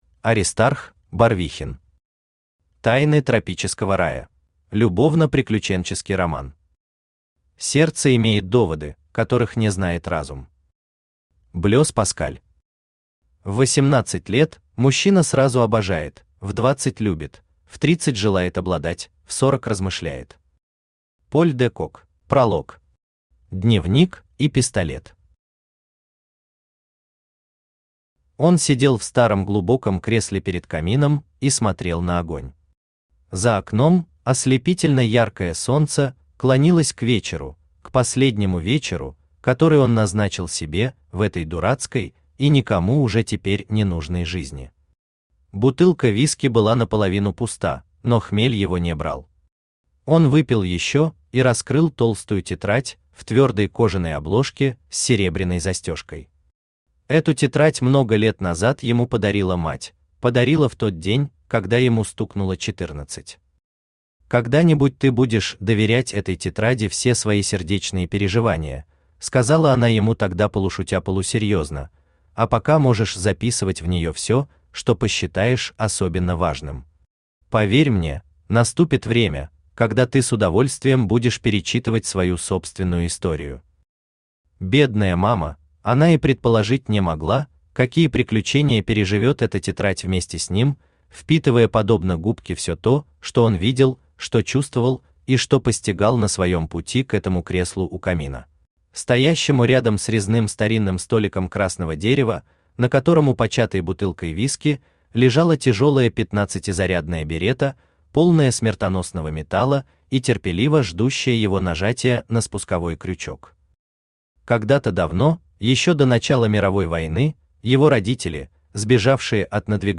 Аудиокнига Тайны тропического рая | Библиотека аудиокниг
Aудиокнига Тайны тропического рая Автор Аристарх Барвихин Читает аудиокнигу Авточтец ЛитРес.